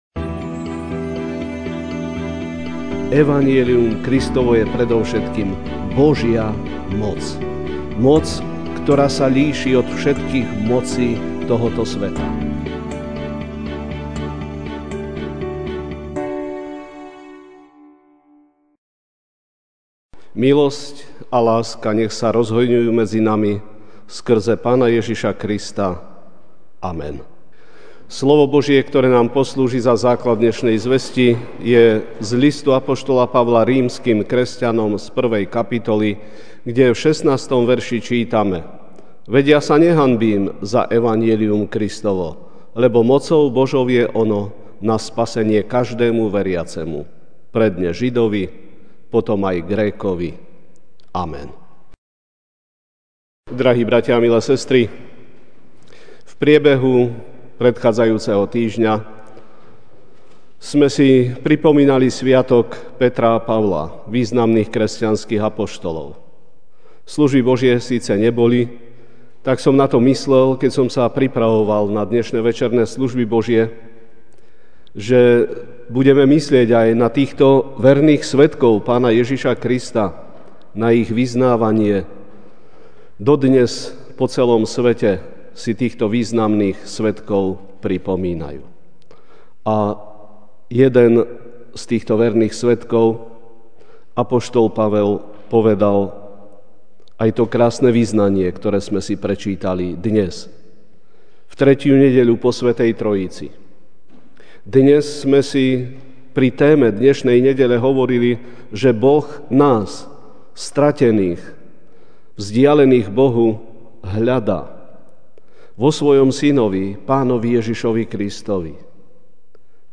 Večerná kázeň: Nehanbím sa! (Rím. 1, 16) Veď ja sa nehanbím za evanjelium (Kristovo): lebo mocou Božou je ono na spasenie každému veriacemu, najprv Židovi, a aj Grékovi.